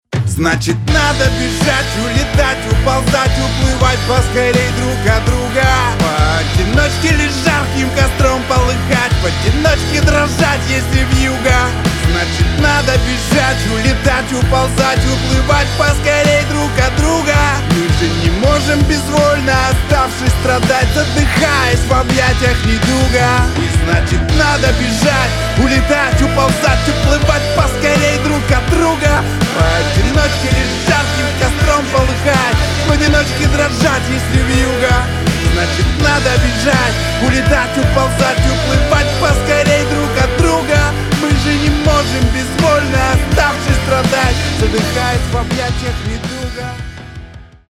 Рок Поп